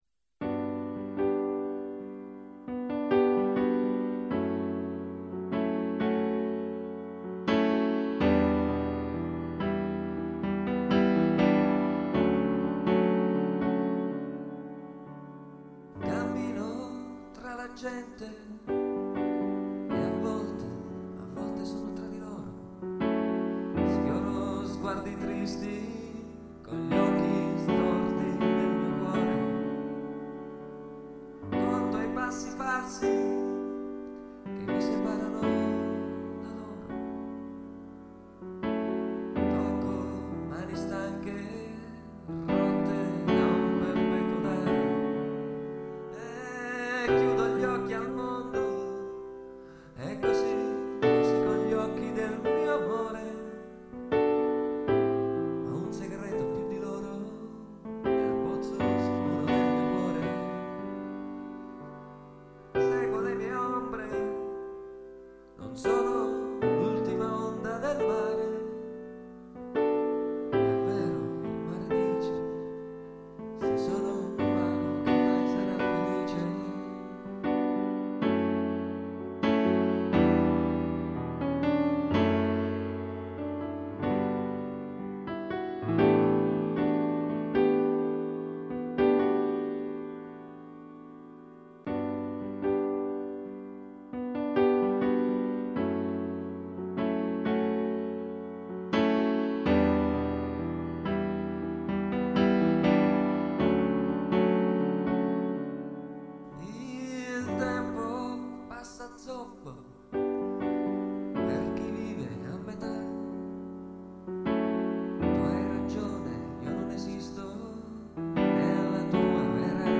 Piano e voce